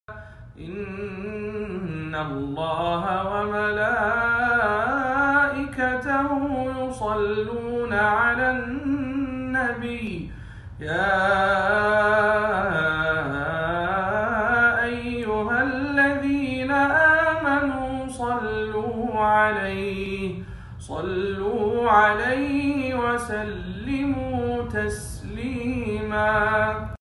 إن الله وملائكته يصلون على النبي- بصوت القارئ